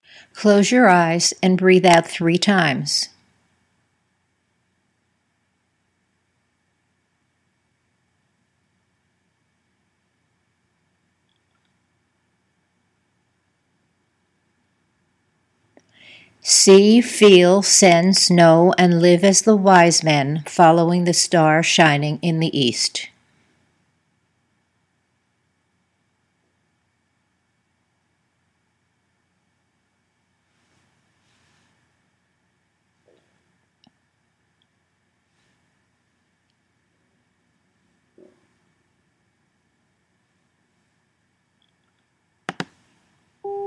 When an Imagery instruction is complete, there are 15 seconds of silence on the tape for your Imagery to emerge.  When that time is over, you’ll hear a tone that is signaling you to breathe out one time and open your eyes.